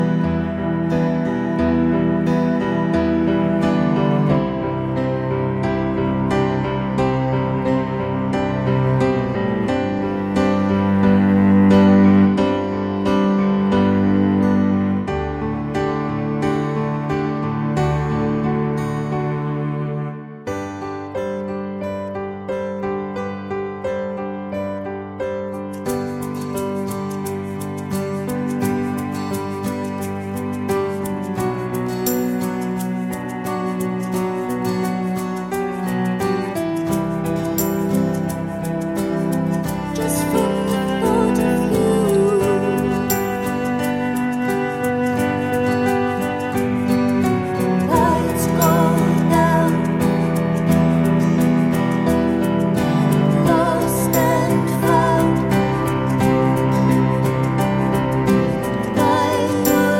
Acoustic Version Pop (2000s) 3:42 Buy £1.50